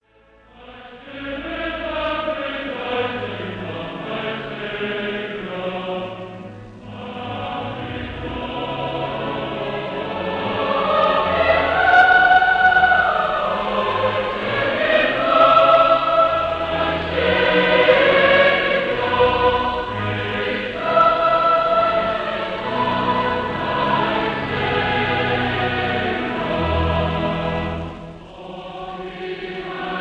This is a private recording